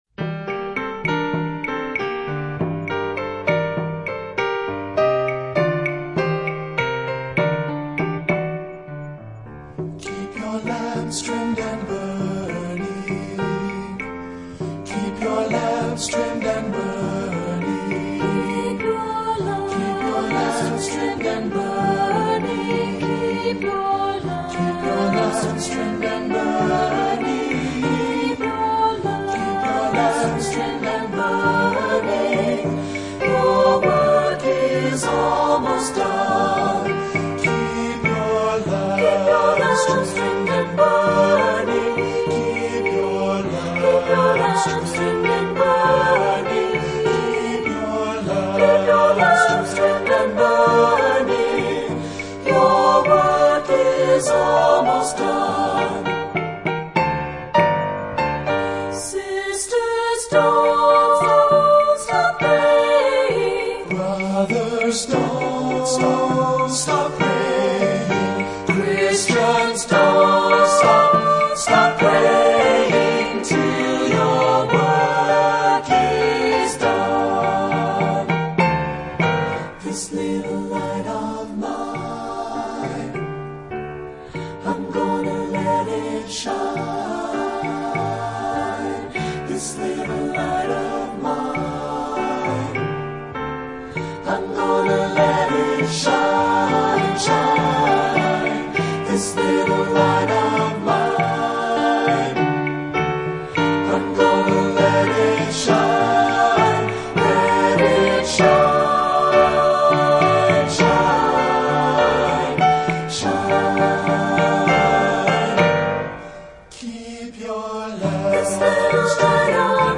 Composer: African American Spiritual
Voicing: SAT